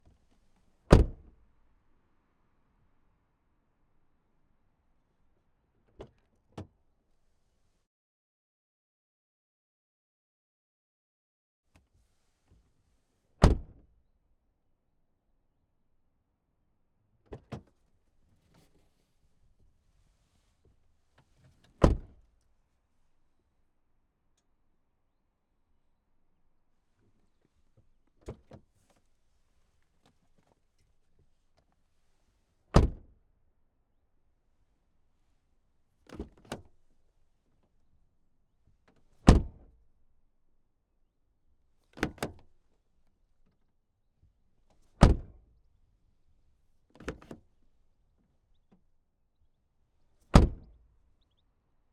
Kia Niro Hybrid - t17 - VAR SFX - Door from the Outside - MS Decoded - RSM191.wav